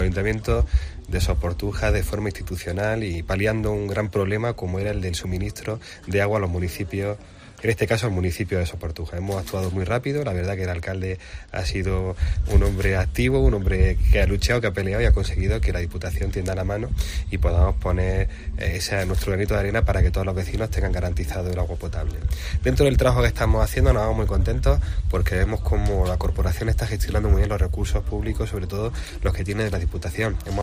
Francis Rodríguez, Presidente de la Diputación